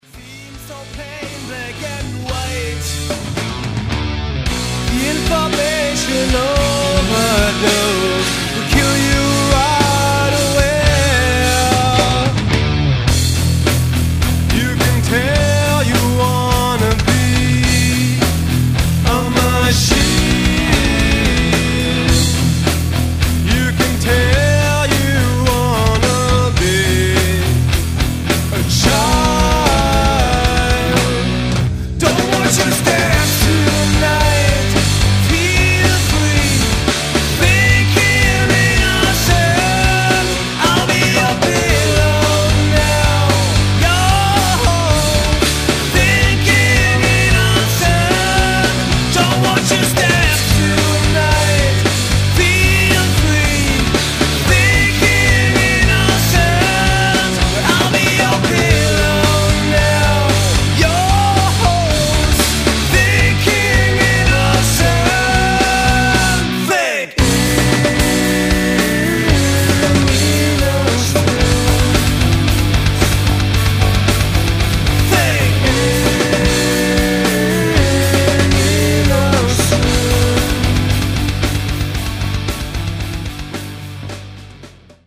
Gut, dass wir den Song nochmal runtertransponiert haben.